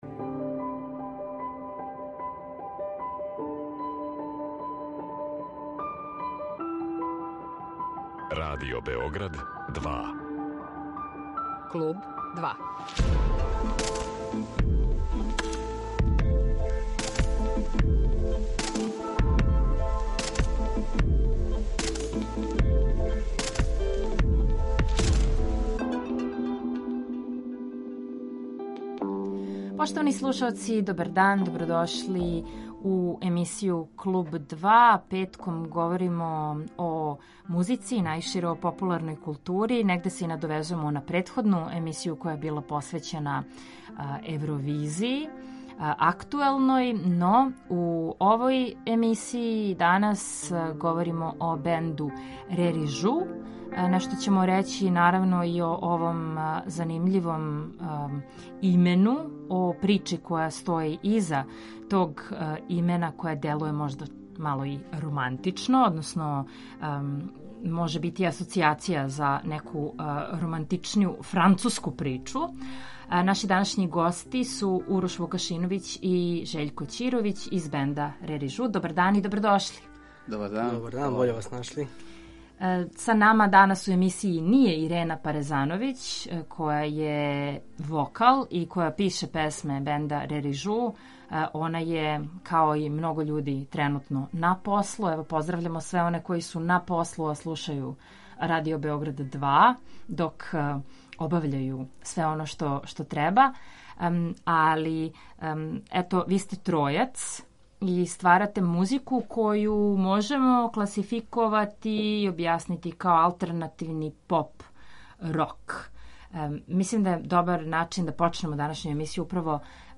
Овај бенд бави се алтернативином поп/рок музиком, са елементима експерименталног.